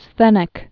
(sthĕnĭk)